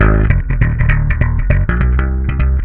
SLAPBASS2 -R.wav